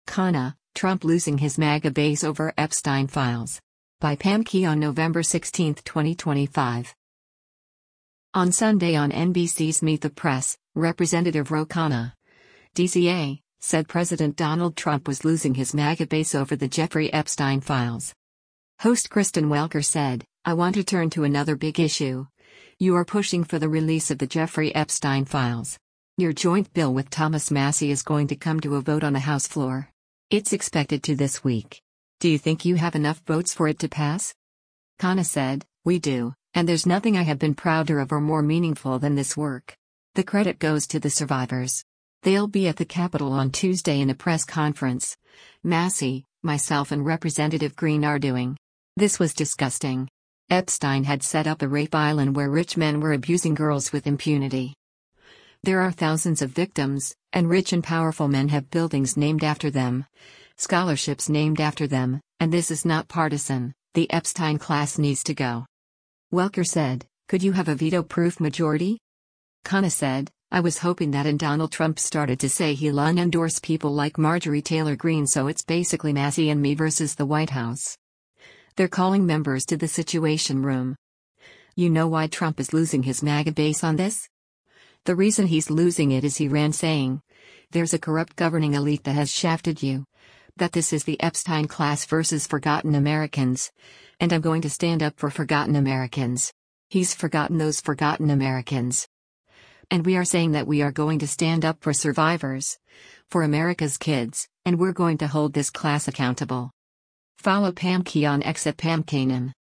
On Sunday on NBC’s “Meet the Press,” Rep. Ro Khanna (D-CA) said President Donald Trump was “losing his MAGA base” over the Jeffrey Epstein files.